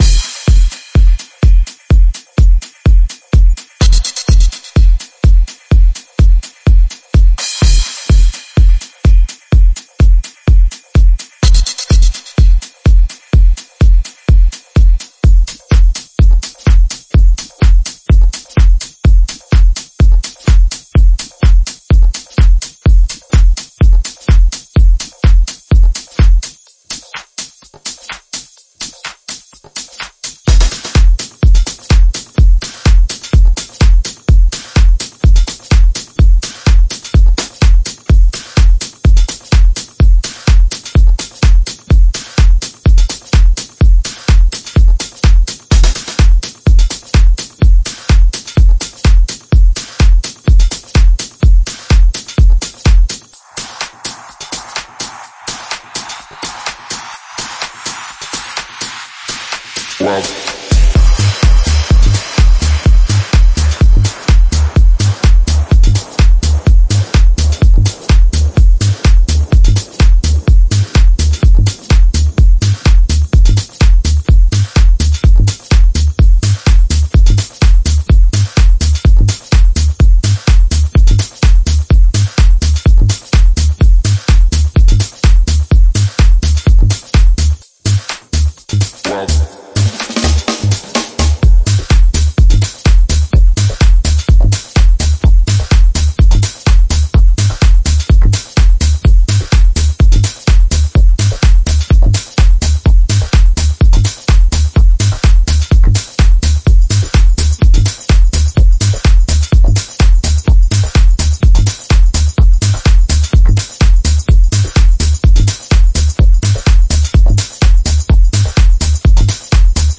A track 'Tech House' Ready for a Dancefloor!